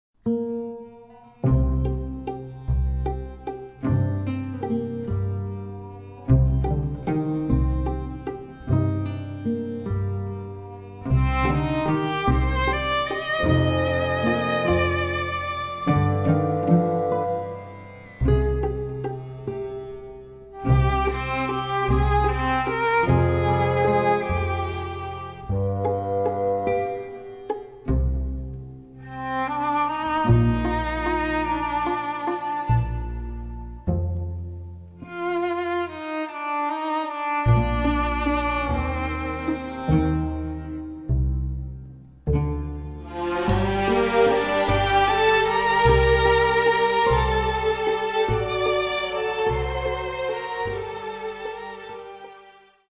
intimate score
on guitar